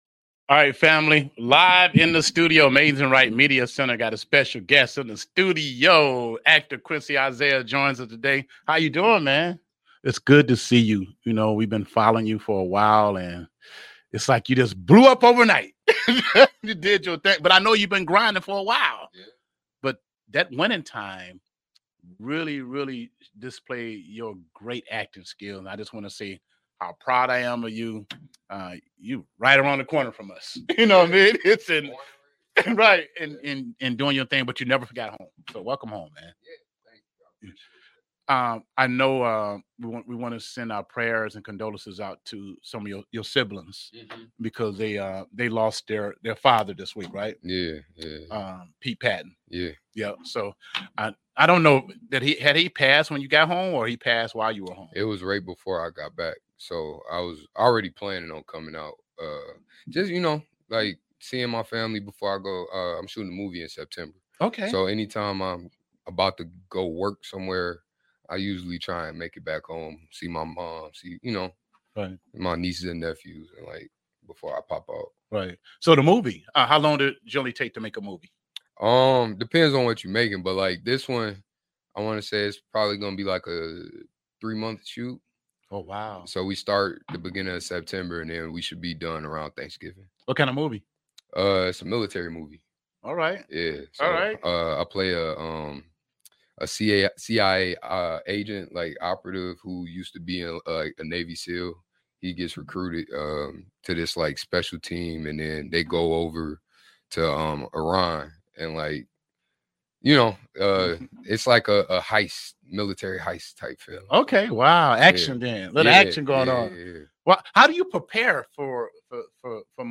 Live with Actor Quincy Isaiah